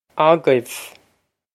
agaibh a-giv
This is an approximate phonetic pronunciation of the phrase.